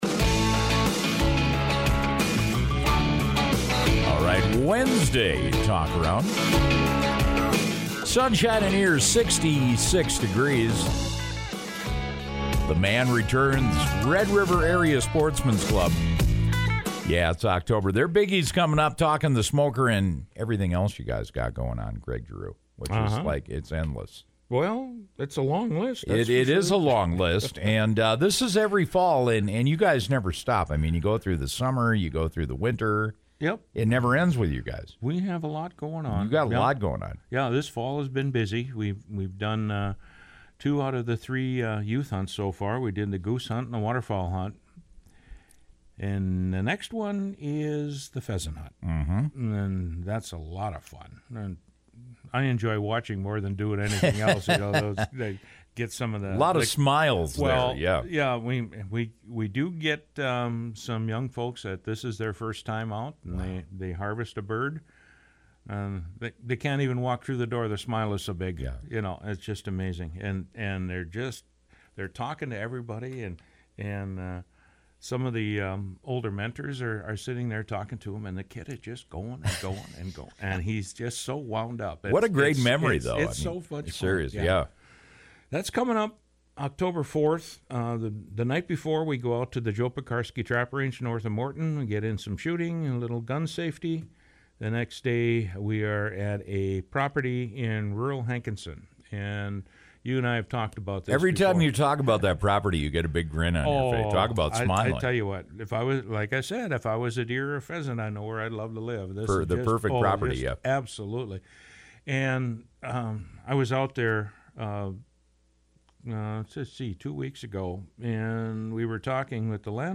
stopped by to talk more about the event this morning.